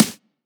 edm-snare-65.wav